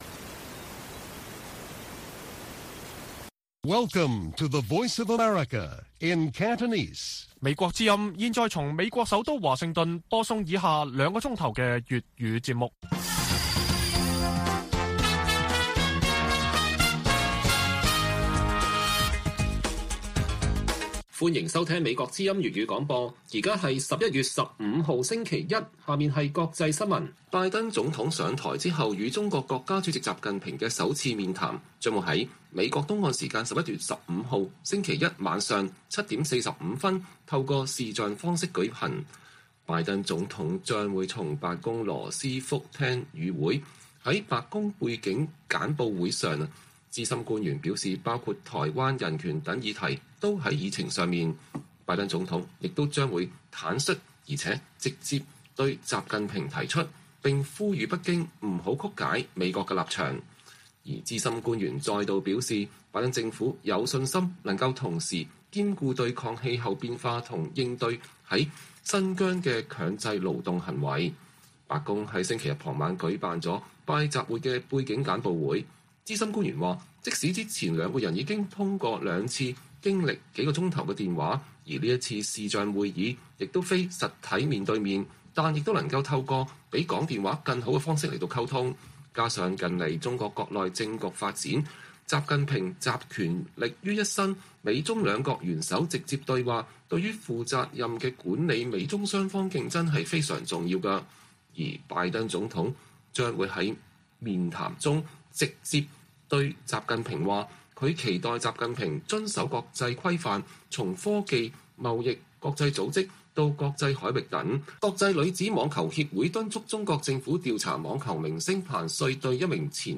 粵語新聞 晚上9-10點: 拜習會週一晚登場 拜登將“坦率直接”提台灣、人權等議題